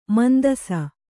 ♪ mandasa